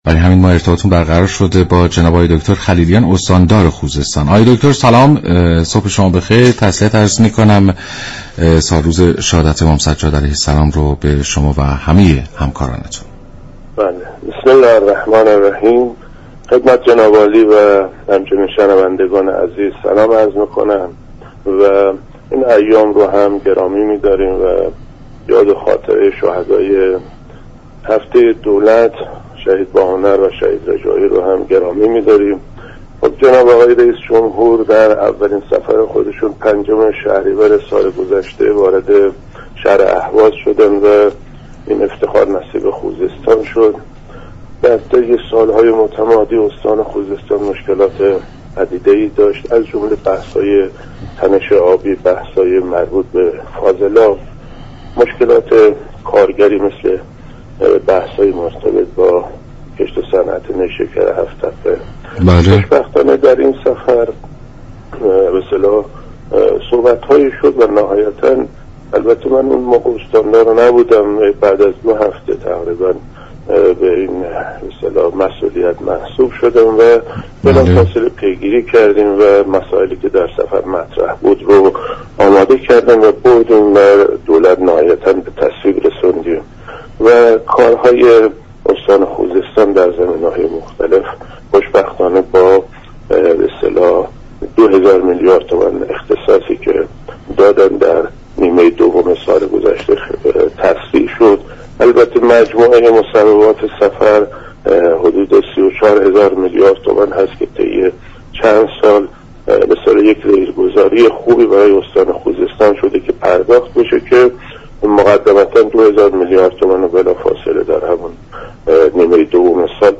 به گزارش شبكه رادیویی ایران، صادق خلیلیان استاندار استان خوزستان در برنامه «سلام صبح بخیر» رادیو ایران ضمن گرامیداشت هفته دولت، درباره فعالیت های استان در یكسال گذشته گفت: استان خوزستان طی سال های گذشته مشكلات فراوانی نظیر تنش آبی، وجود فاضلاب داشته كه خوشبختانه از سال گذشته تاكنون مشكلات و معضلات استان مورد پیگیری قرار گرفته است.